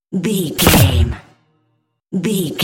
Dramatic hit deep electricity
Sound Effects
Atonal
heavy
intense
dark
aggressive